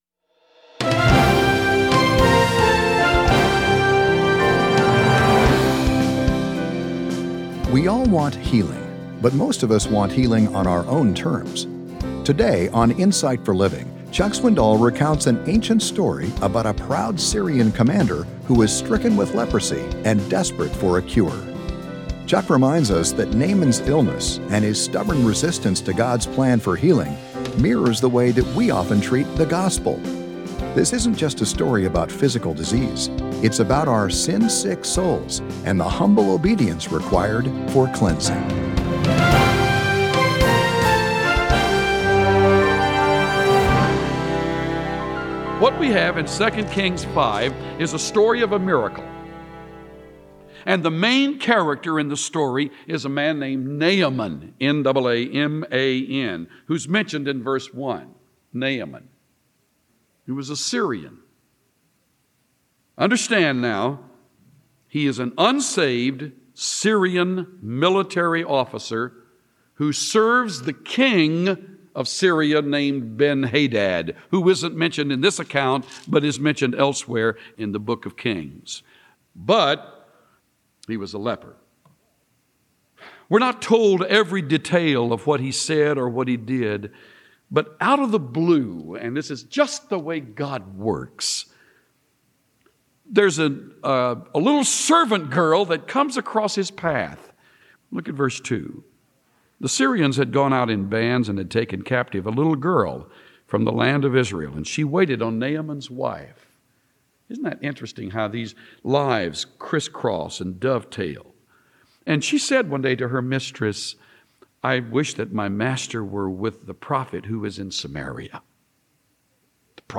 Tune in to hear Pastor Chuck Swindoll teach on the truth found in 2 Kings 5. Discover how Naaman’s story reflects the spiritual journey many people go on as they turn to Jesus Christ.